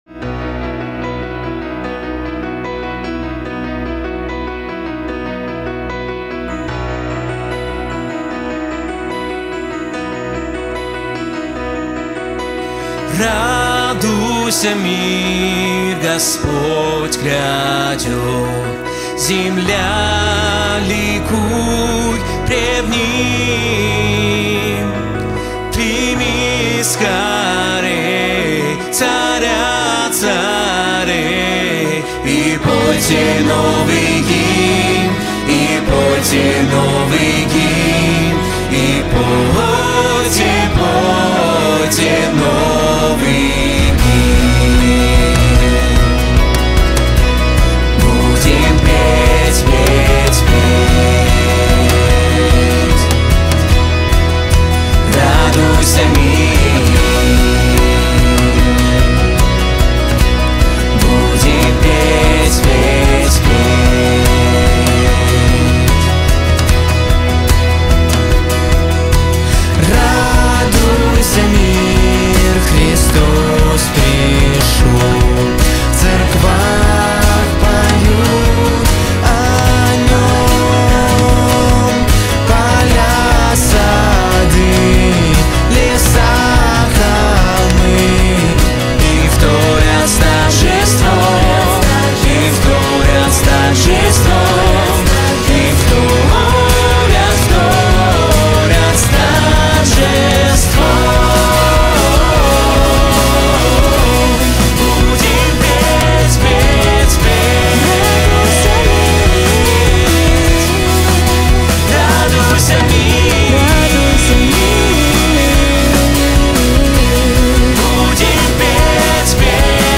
4717 просмотров 1056 прослушиваний 33 скачивания BPM: 148